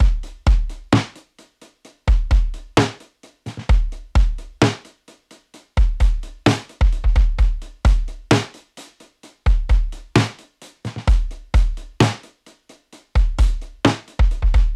80s Touch（DB-30 OFF / ON）
80s-Touch-DRY[661].mp3